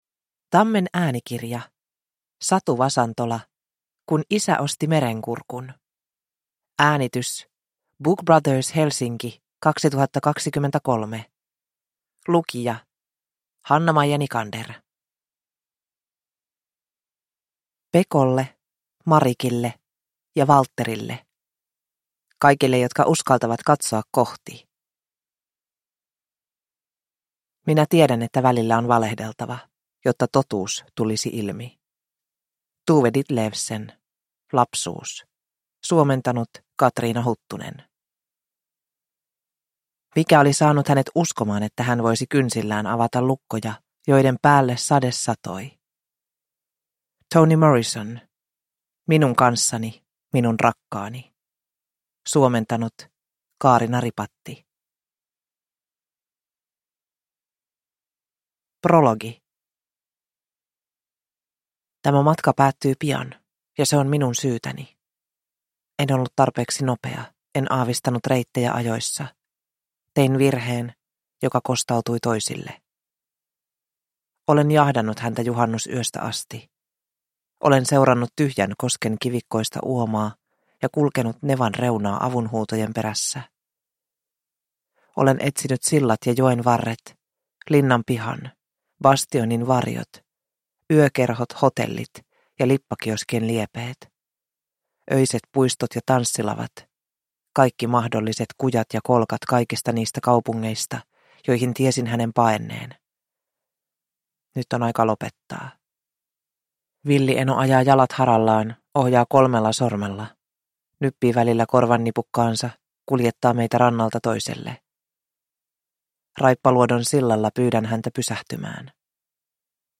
Kun isä osti Merenkurkun – Ljudbok – Laddas ner